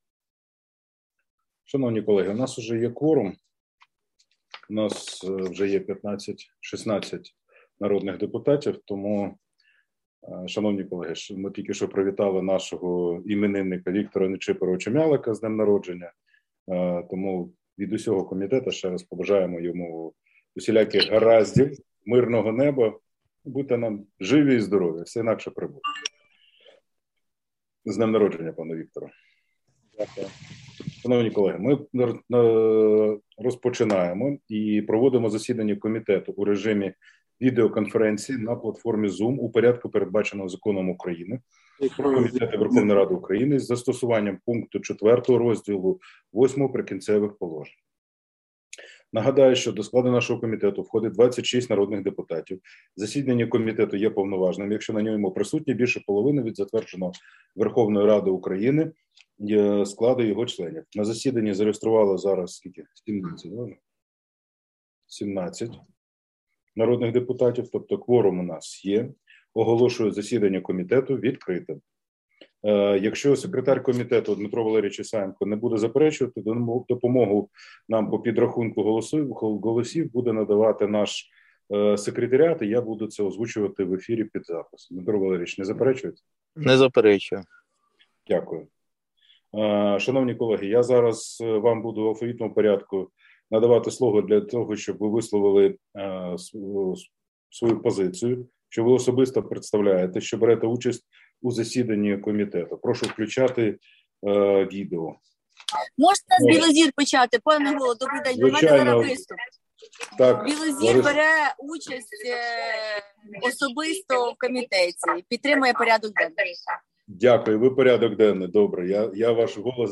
Аудіозаписи засідань Комітету травень 2022 року